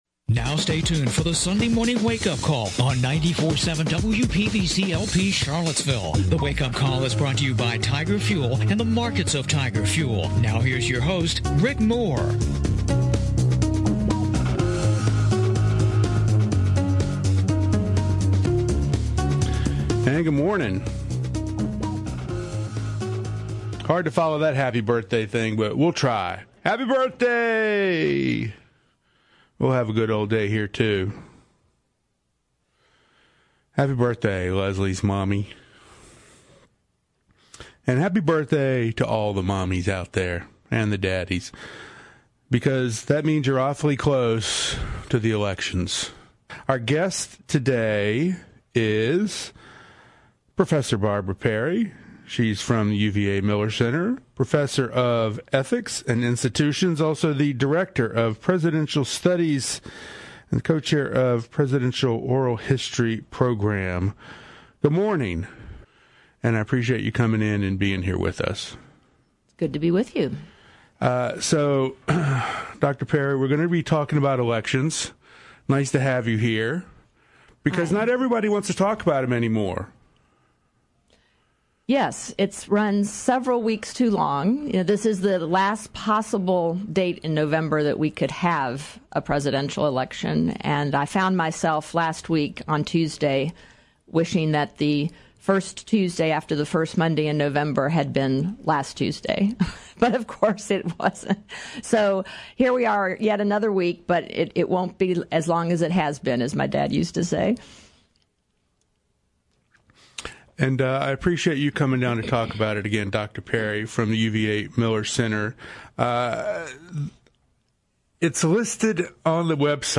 The Sunday Morning Wake-Up Call is heard on WPVC 94.7 Sunday mornings at 11:00 AM.